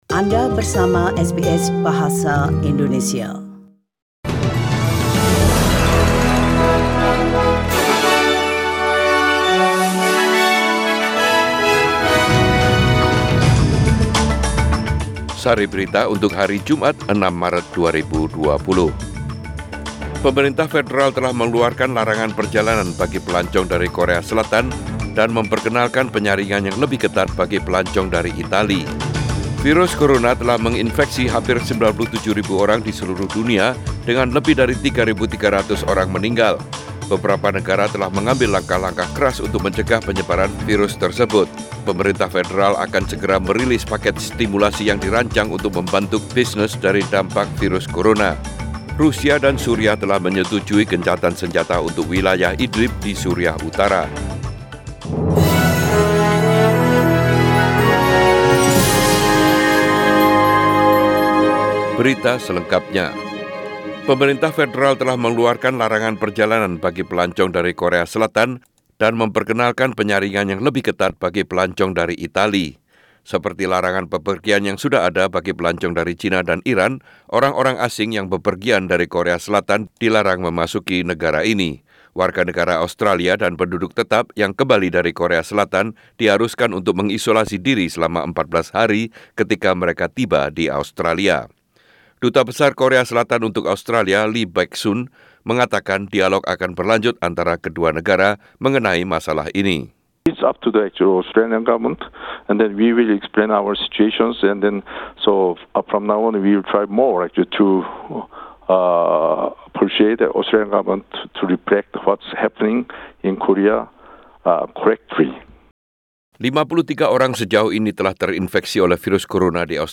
SBS Radio News in Indonesian - 06 Maret 2020